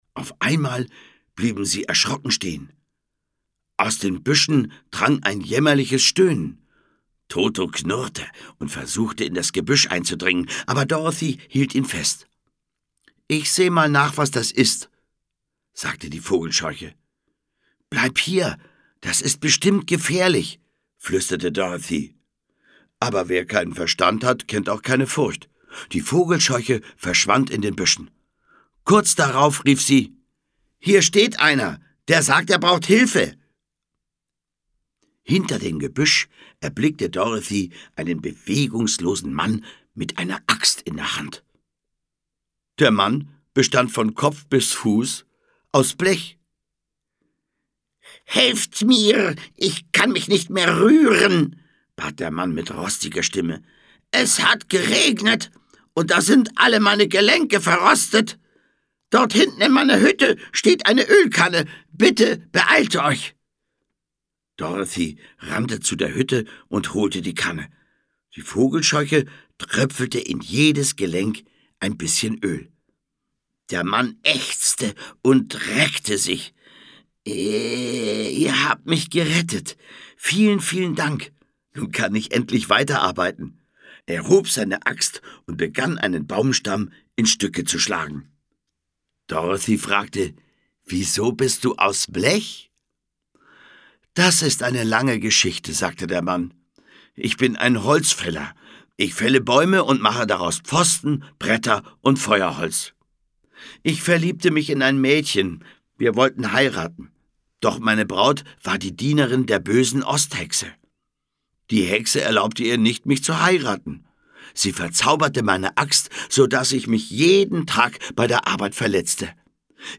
Unter dem Motto "Augen zu - Film ab" erzählen namhafte Sprecher die großen Geschichten der Kinderliteratur neu.
Schlagworte Abenteuer; Kinder-/Jugendliteratur • Abenteuer; Kinder-/Jugendliteratur (Audio-CD) • Dorothy • Hörbuch für Kinder/Jugendliche • Hörbuch für Kinder/Jugendliche (Audio-CD) • Hörbuch; Lesung für Kinder/Jugendliche • Kindercassetten • Kinderklassiker • Zauberer